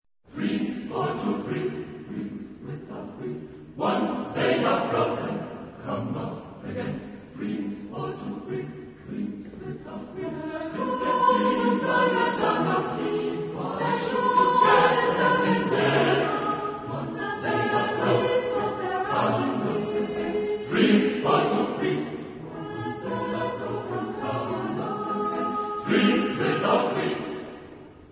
Type of Choir: SATB  (4 mixed voices )
Consultable under : JS-19e Profane A Cappella